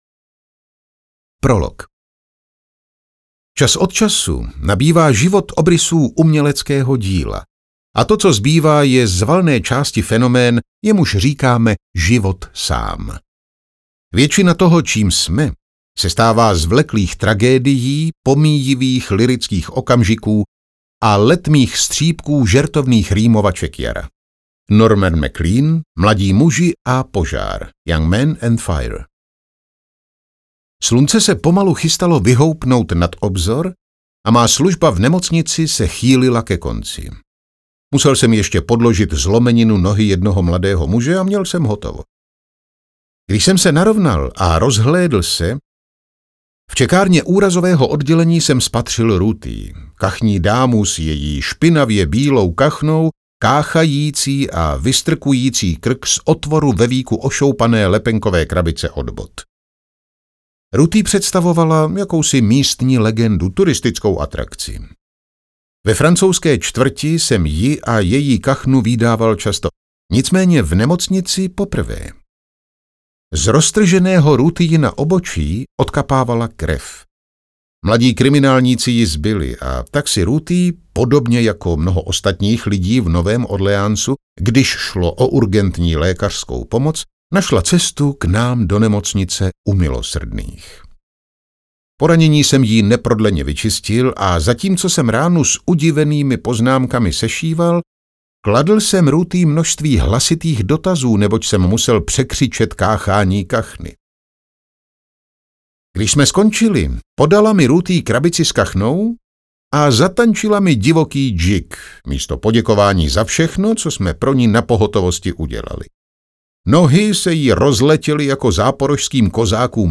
Audiokniha Každé hluboké nadechnutí - Wes Ely | ProgresGuru